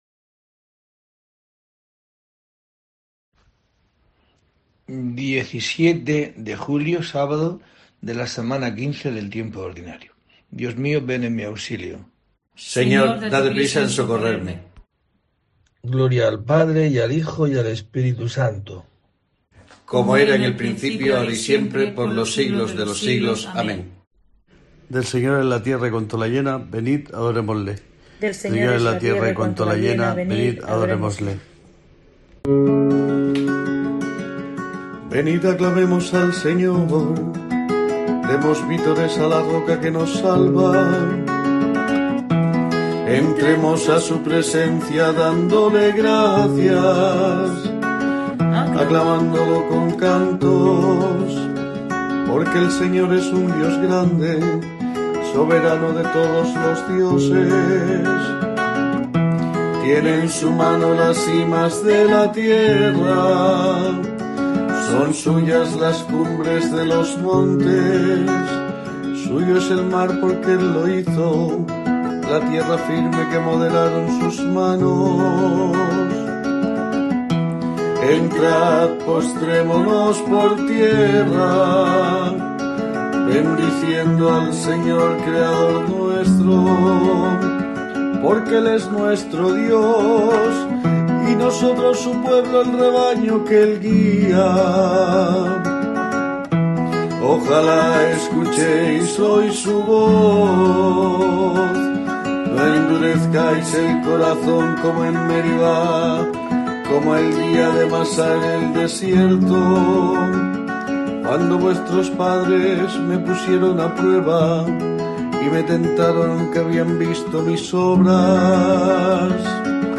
17 de julio: COPE te trae el rezo diario de los Laudes para acompañarte